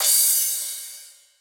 edm-crash-05.wav